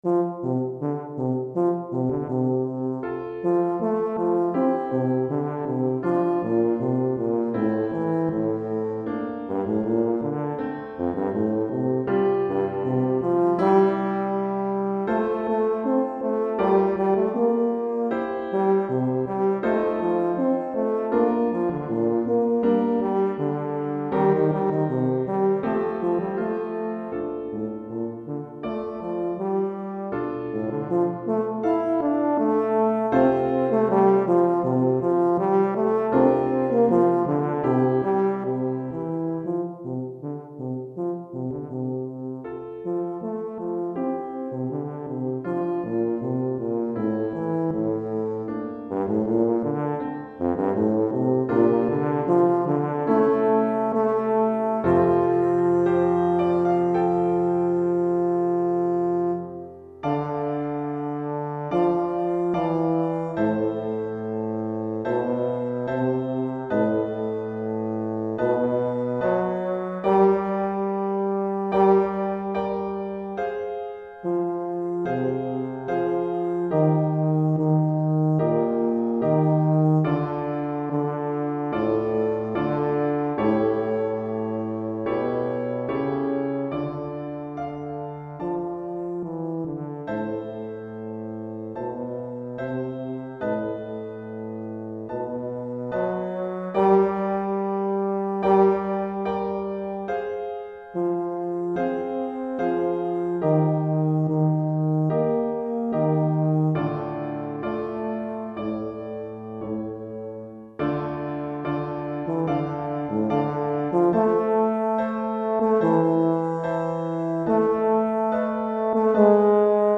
Pour saxhorn ou euphonium et piano